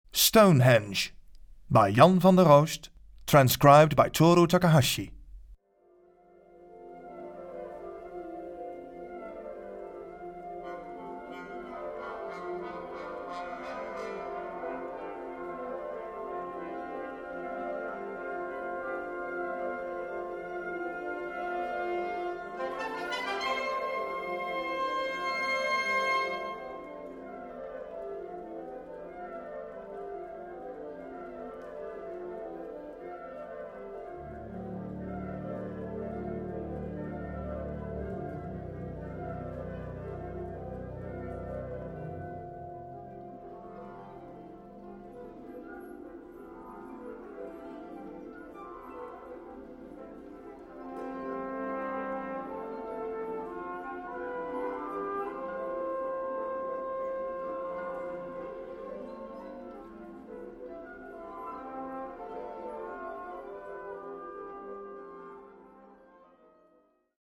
Concert Band Version
Key: F lydian mode